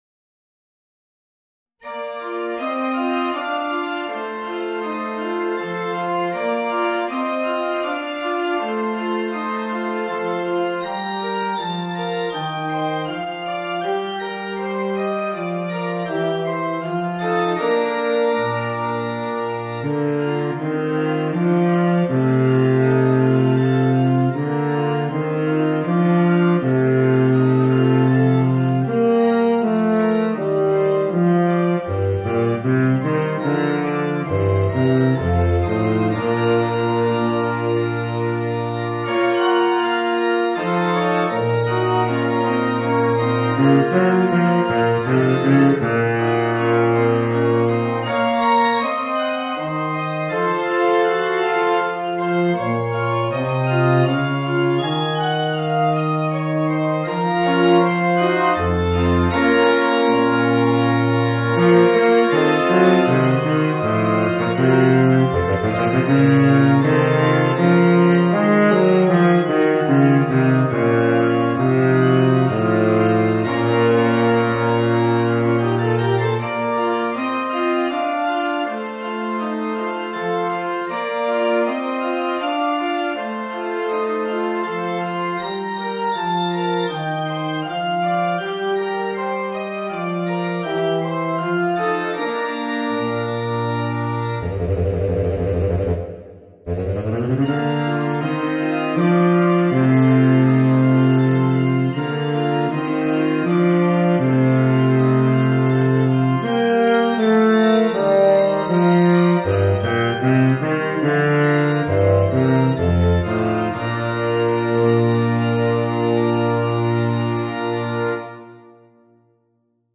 Saxophone baryton & piano